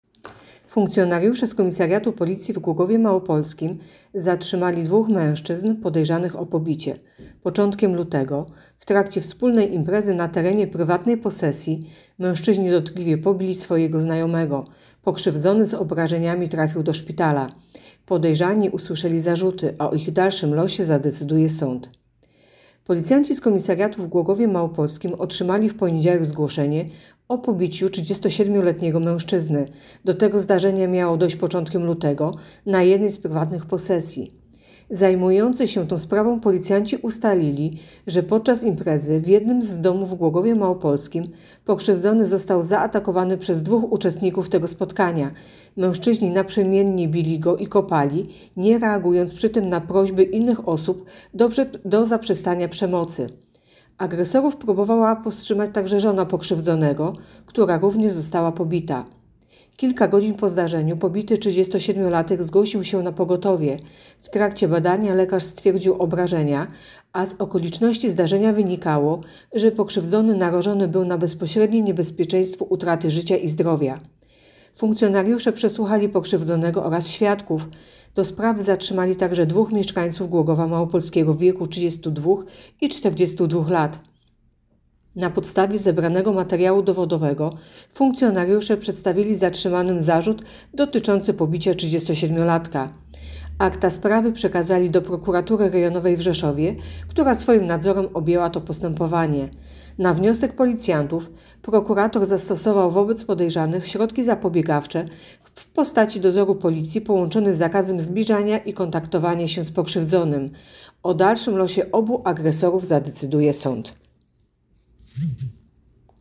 Opis nagrania: Nagranie głosowe informacji policjanci zatrzymali podejrzanych o pobicie.